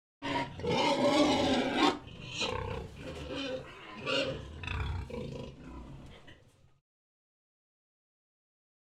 piano/keys/strings
guitar